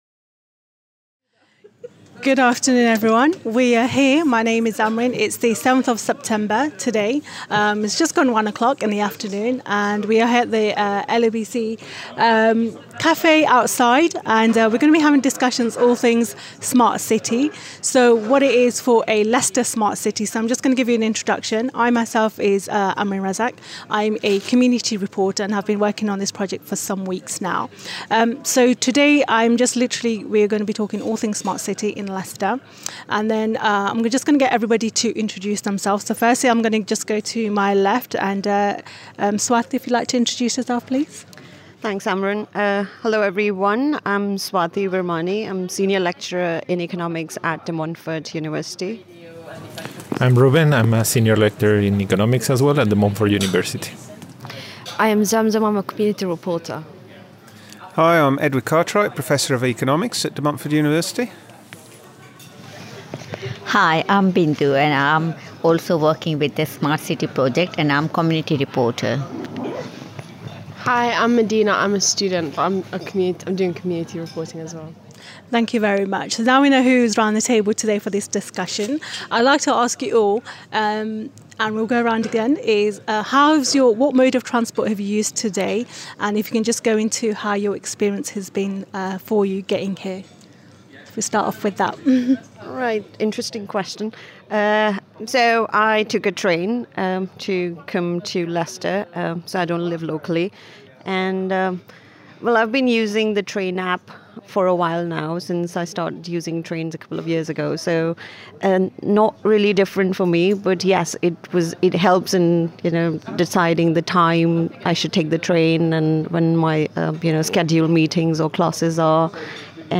Leicester Smart City Vox-Pops Wednesday 14th September